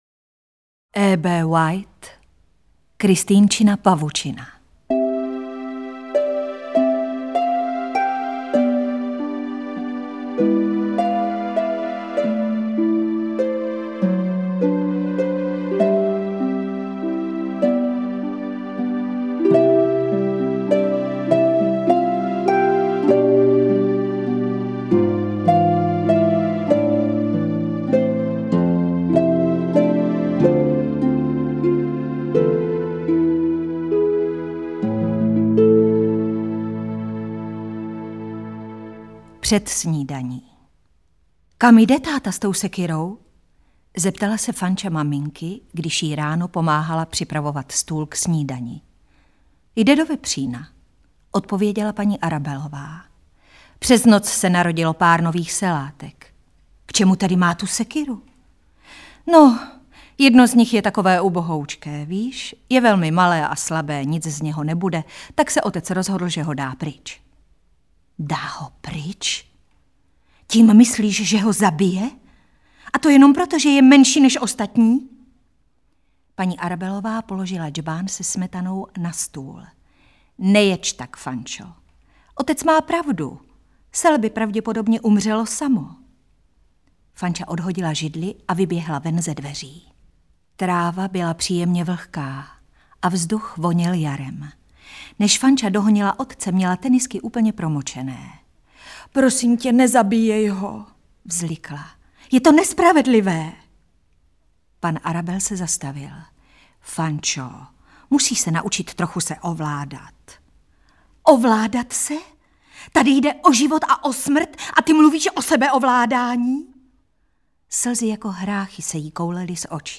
Interpret:  Hana Maciuchová
AudioKniha ke stažení, 18 x mp3, délka 3 hod. 19 min., velikost 455,3 MB, česky